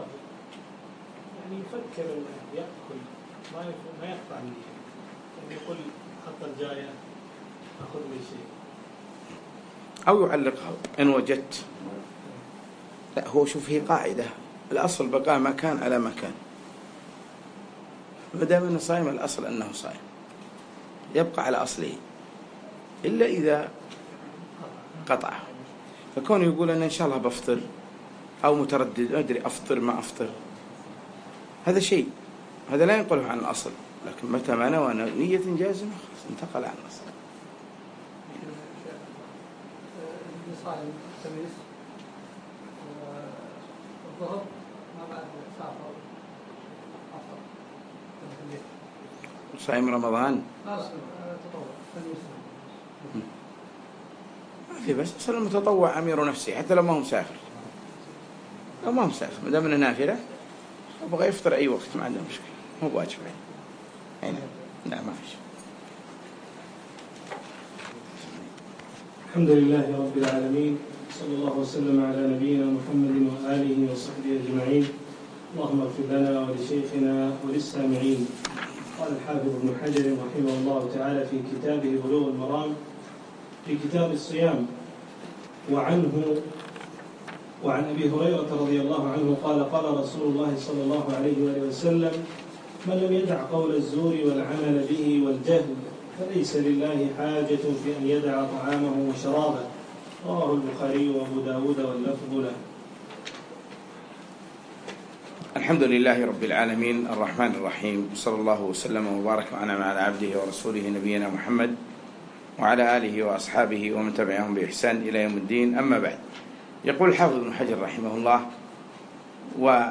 يوم الخميس 26 شعبان 1437هـ الموافق 2 6 2016 في مكتبة الشيخ ابن حجر قطر
الدرس الثاني